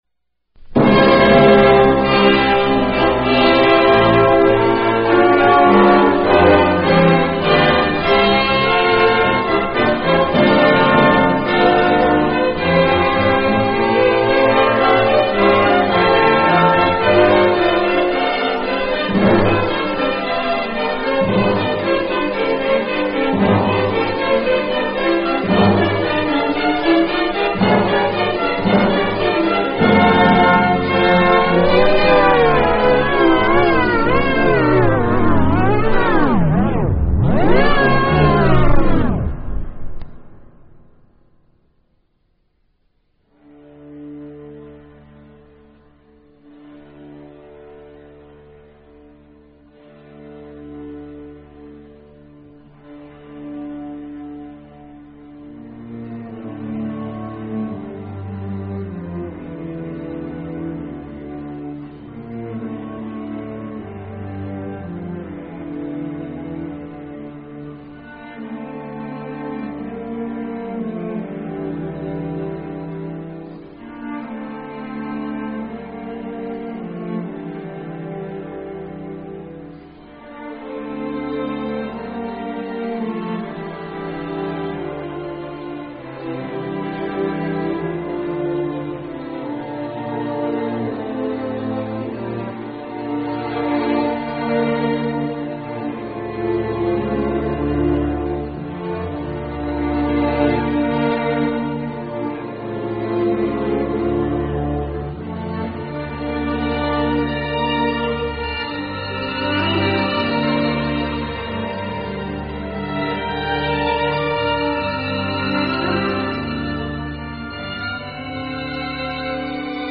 Listen to the interview from the original broadcast: Audio Part 1 [0:36:48–1:24:57] / Audio Part 2 [0:30:50–1:22:00].